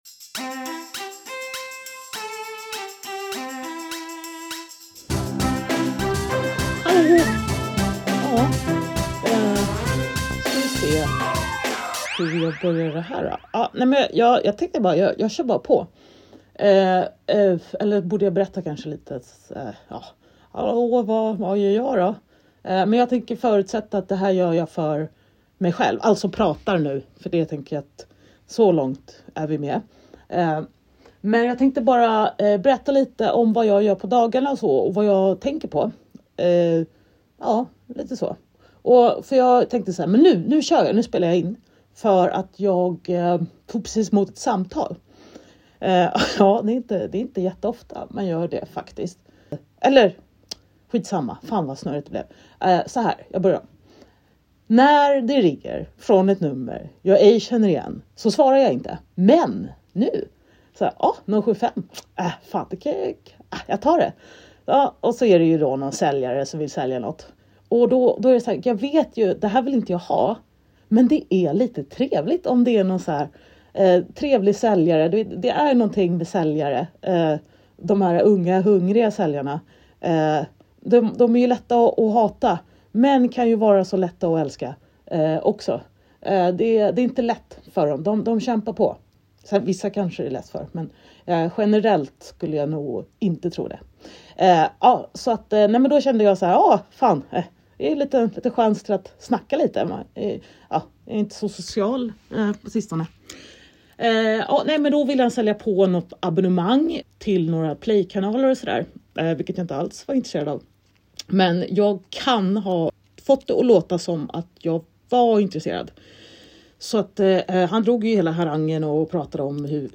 Nu har hon även startat upp en ensampodd som hon spelar in hemma, och jag tänkte upplåta patreonsidan som en testmiljö för den. Det är 15-20 min stream of consciousness som kanske kan glädja och ifrågasätta konventioner.